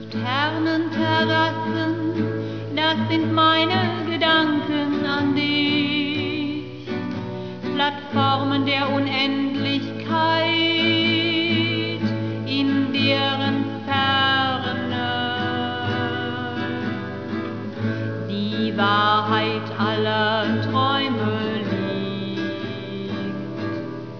typische poetische Varieté-Stimmung